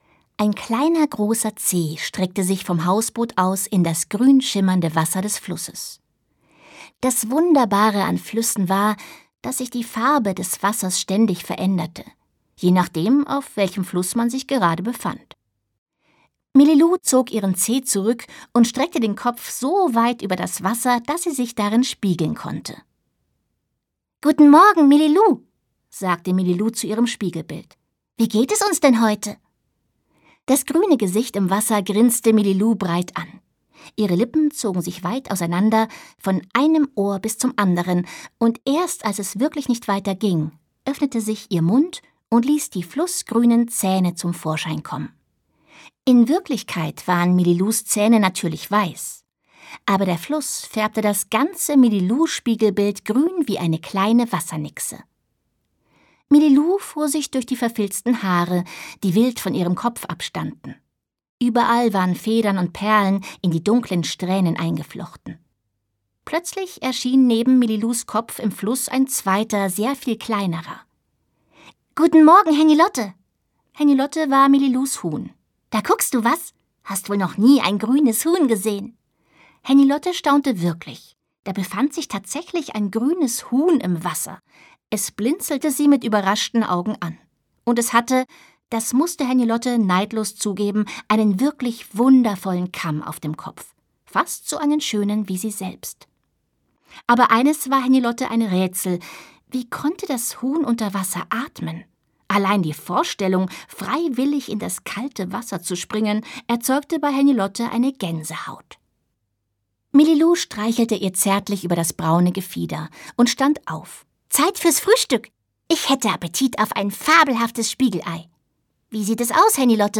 Sabine Bohlmann (Sprecher)
Schlagworte Abenteuer • Achtsamkeit • Behinderung • Blind • Blindheit • Diversität • Fantasie • Freundschaft • Hausboot • Hörbuch ab 8 • Hörbuch ab 9 • Hörbuch für Kinder • Huhn • Inklusion • Kinderhörbuch • Natur • Tiere • Willow • Ziege